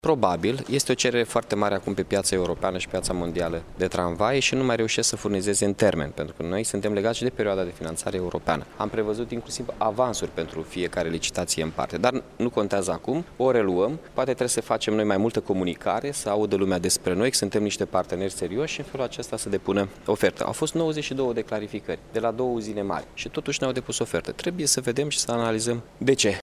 Referindu-se la licitaţia pentru tramvaie de la Iaşi, Mihai Chirica a spus că aceasta va fi reluată deoarece până acum nici o firmă nu s-a înscris la licitaţie.